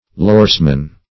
Search Result for " loresman" : The Collaborative International Dictionary of English v.0.48: Loresman \Lores"man\, n. [Lore learning + man.]